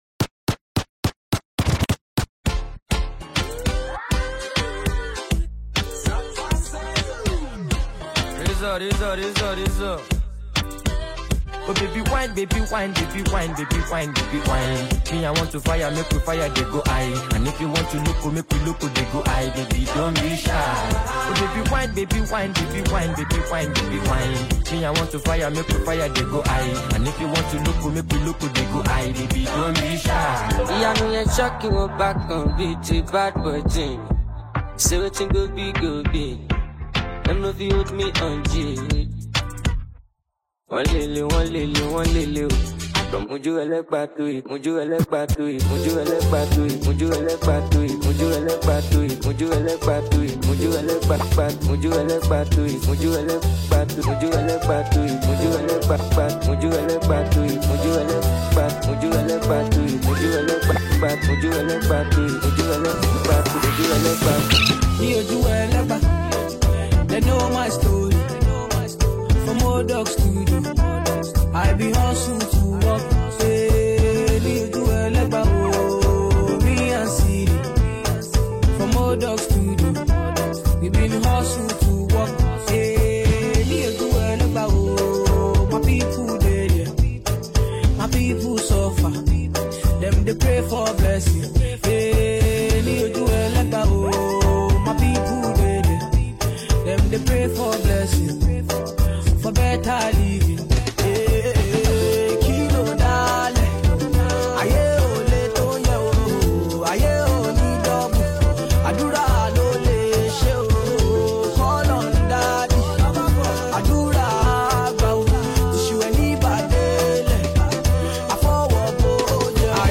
Talented Nigerian disc jockey